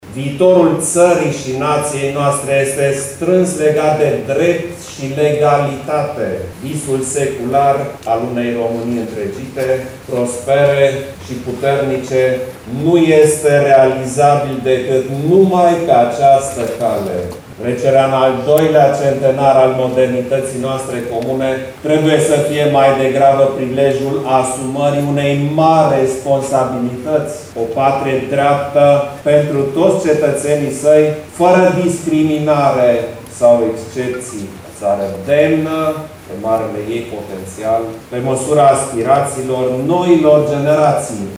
El a spus, în cadrul unei eveniment solemn desfășurat în curtea interioară a Cetății de Scaun, că spiritul Unirii din urmă cu 100 ani implică mari responsabilități.